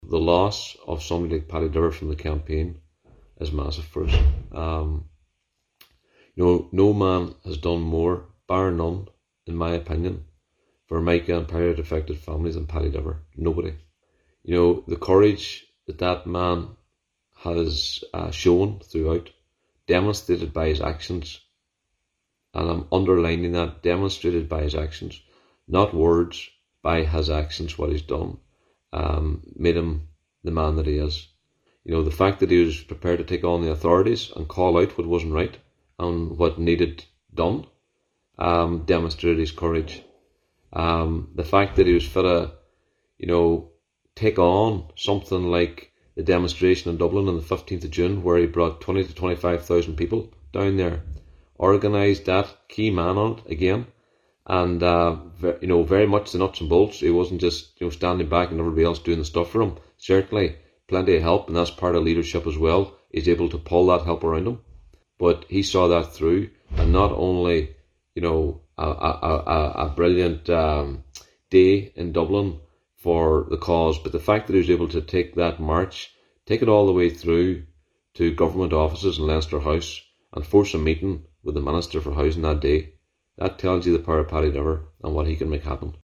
Speaking on social media last night